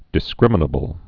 (dĭs-krĭmə-nə-bəl)